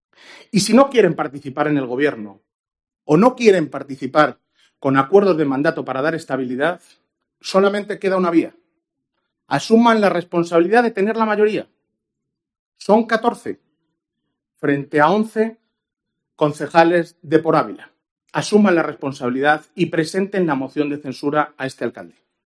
Alcalde de Ávila. Pleno Cuestión de Confianza